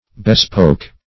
Bespoke \Be*spoke"\ (b[-e]*sp[=o]k"),